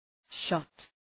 Προφορά
{ʃɒt}